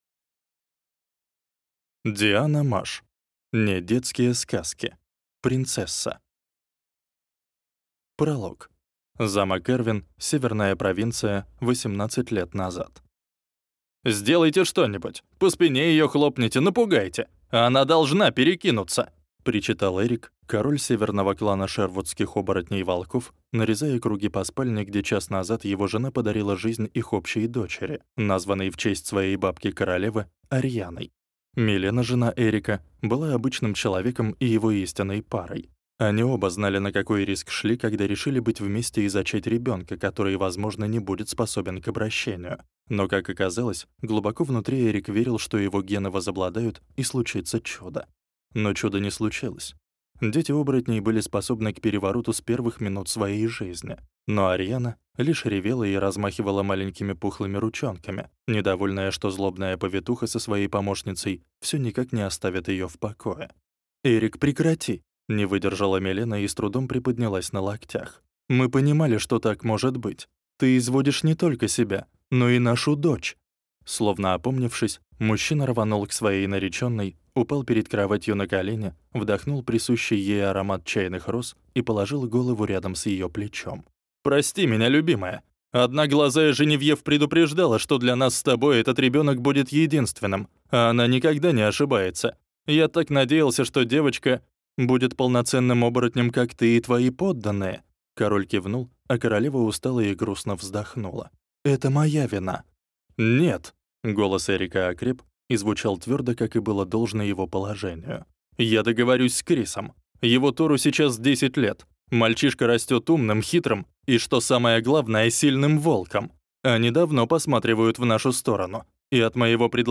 Аудиокнига (Не) детские сказки: Принцесса | Библиотека аудиокниг